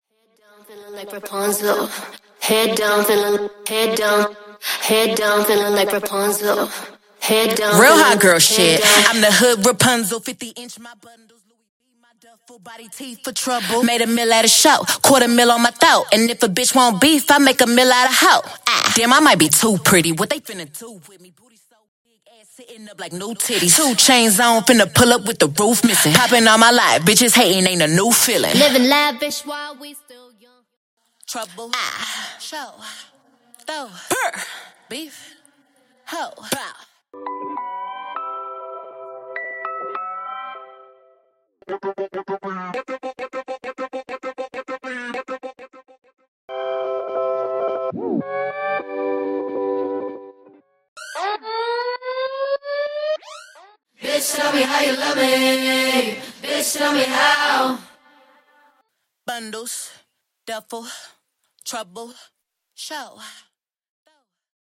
Studio Vocal Choir Harmony Stem